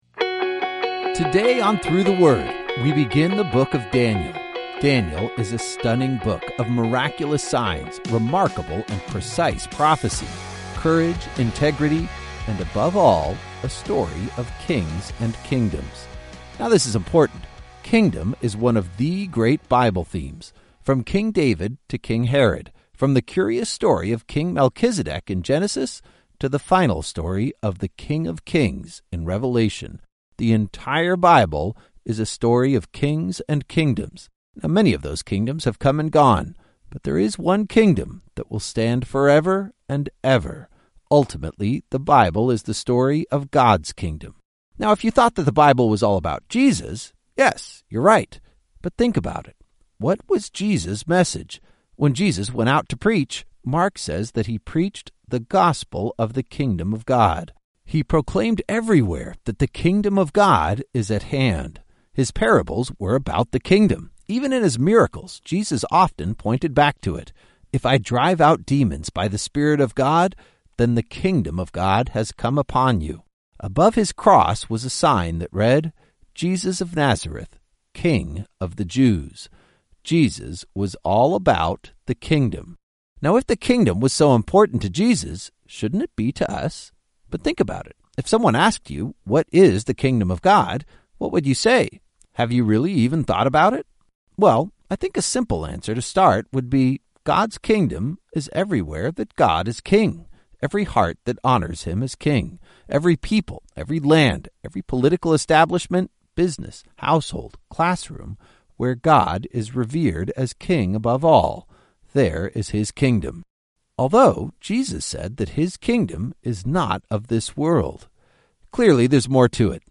19 Journeys is a daily audio guide to the entire Bible, one chapter at a time. Each journey takes you on an epic adventure through several Bible books, as your favorite pastors clearly explain each chapter in under ten minutes. Journey #3 is Foundations, where Genesis takes us back to our origins, Daniel delivers phenomenal prophecies, and Romans lays out the heart of the gospel.